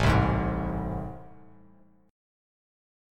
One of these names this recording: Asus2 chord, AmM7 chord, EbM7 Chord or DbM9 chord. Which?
AmM7 chord